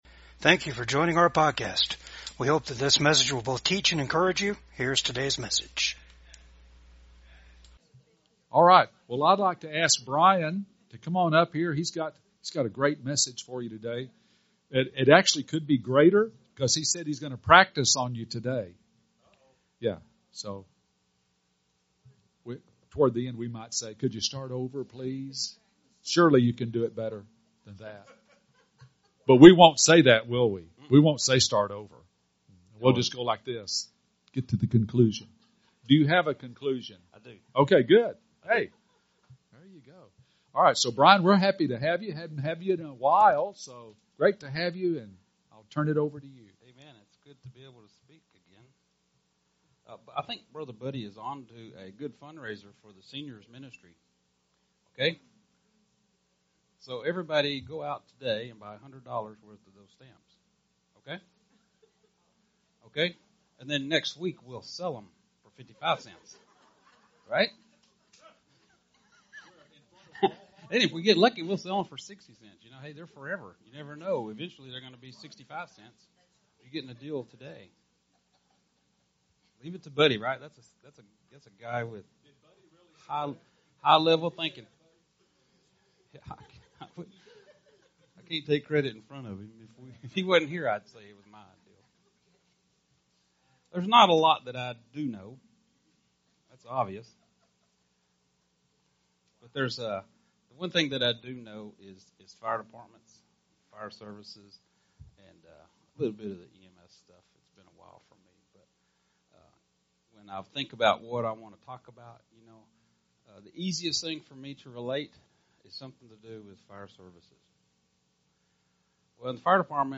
Service Type: VCAG WEDNESDAY SERVICE